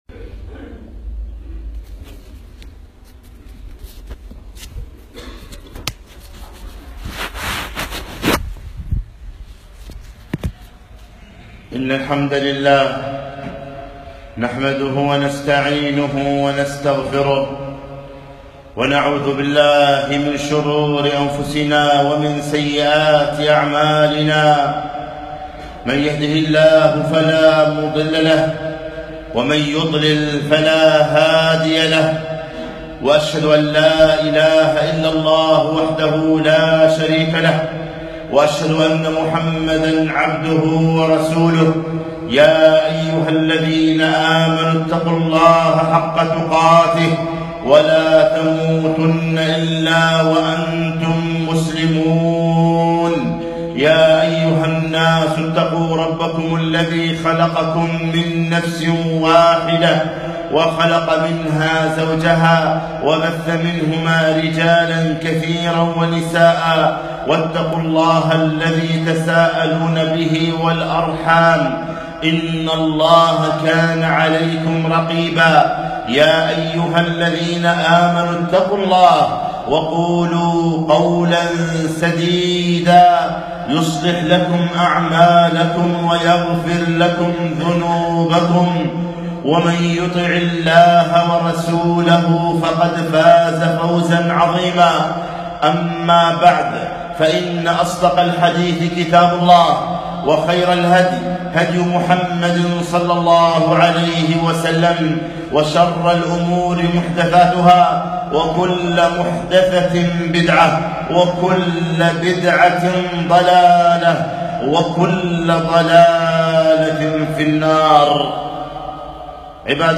خطبة - فاعلم أنه لا إله الا الله